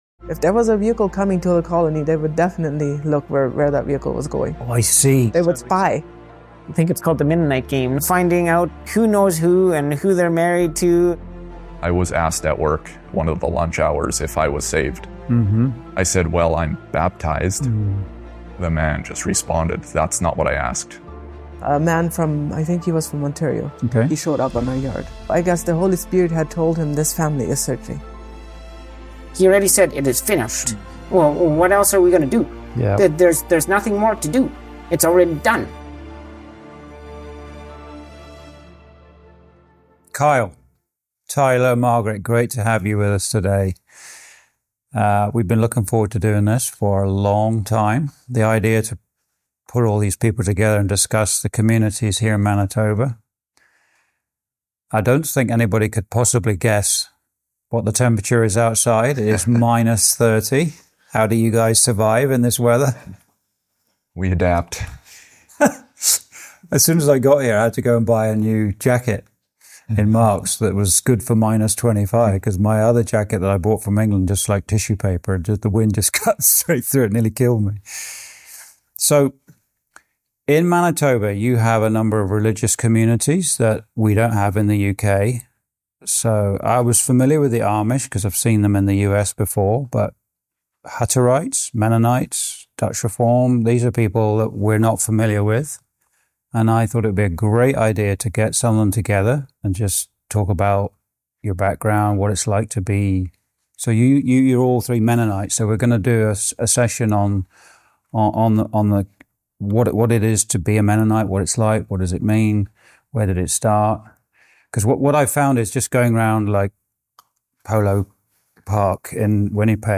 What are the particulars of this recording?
Recorded in Manitoba, Canada, 24th Feb 2026